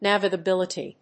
音節nav・i・ga・bil・i・ty 発音記号・読み方
/n`ævɪgəbíləṭi(米国英語)/